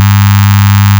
Note: some odd behavior happening with bandstop.
less10bandstop.wav